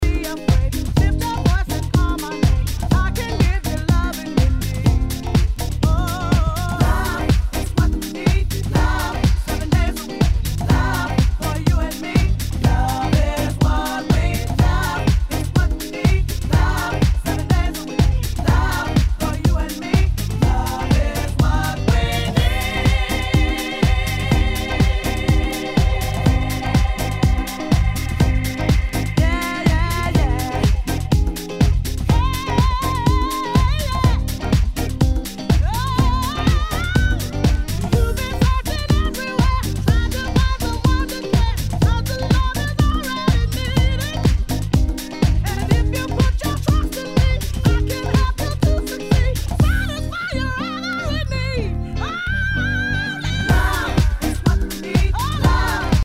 HOUSE/TECHNO/ELECTRO
ナイス！ディープ・ヴォーカル・ハウス！
全体にチリノイズが入ります